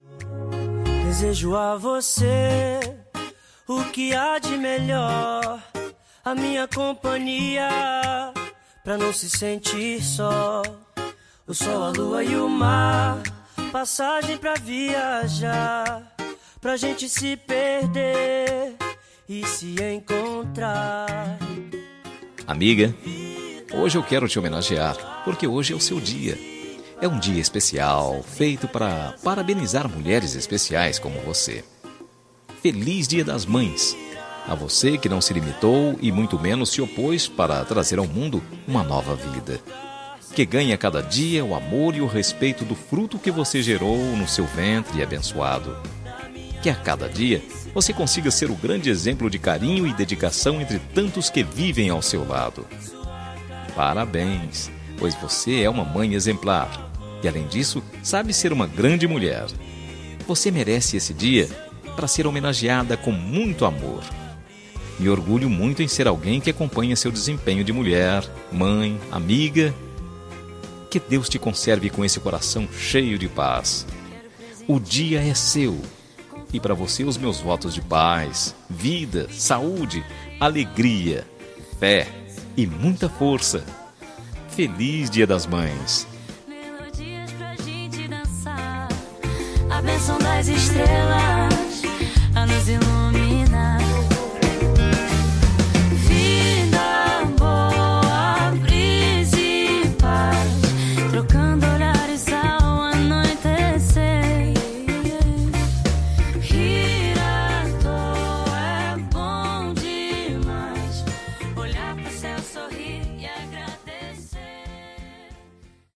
Temas com Voz Masculina
Telemensagens Dia das Mães para Amiga são telemensagens para ser entregue via ligação telefônica.